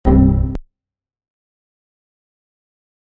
windows_error_sound.mp3